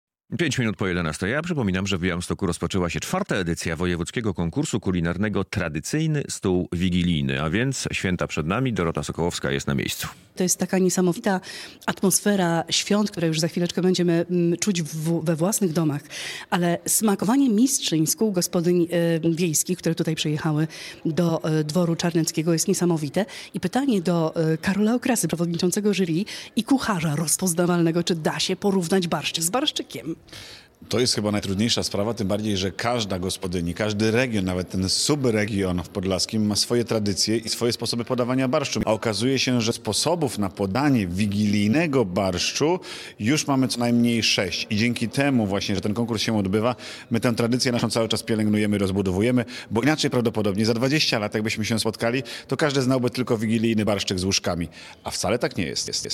Trwa kolejna edycja konkursu "Tradycyjny stół wigilijny” - relacja